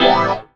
17CHORD02 -R.wav